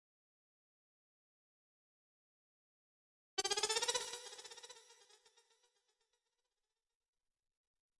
合成器1
描述：合成器fl工作室
标签： 120 bpm Trap Loops Synth Loops 1.35 MB wav Key : Unknown
声道立体声